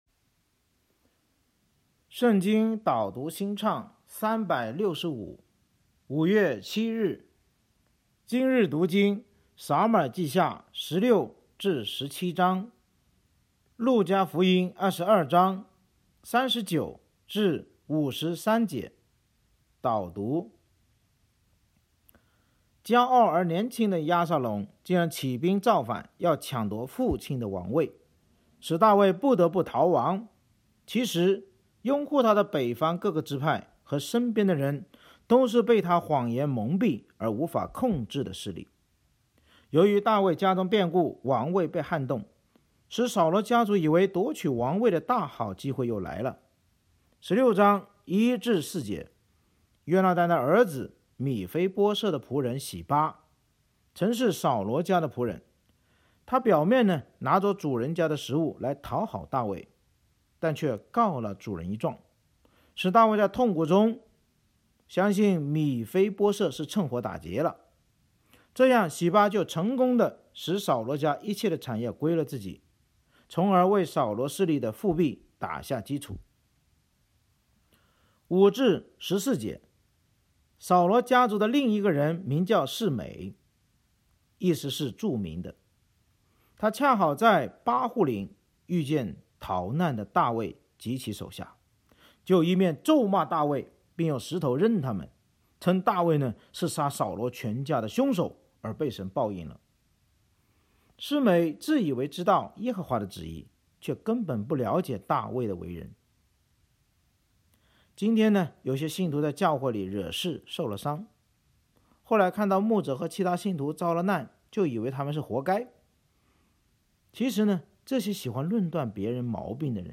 圣经导读&经文朗读 – 05月07日（音频+文字+新歌）
【导读新唱365】朗读5月7日.mp3